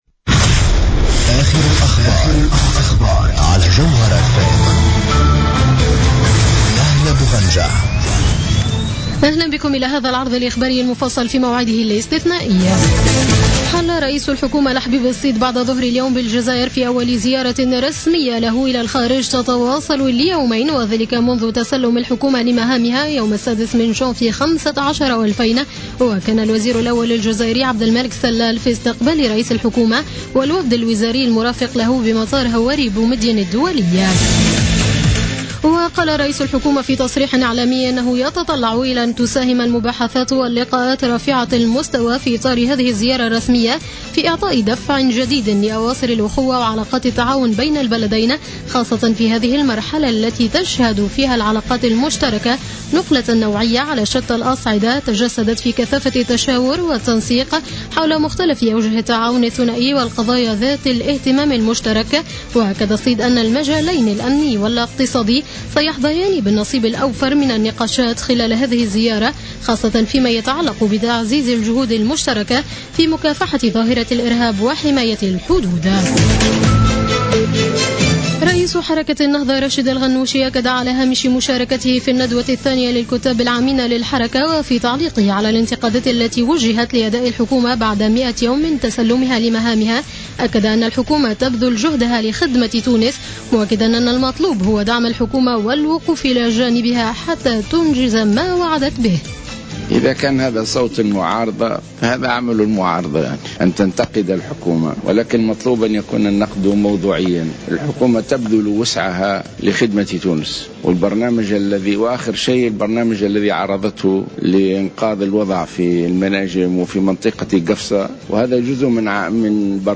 نشرة أخبار السابعة مساء ليوم السبت 16 ماي 2015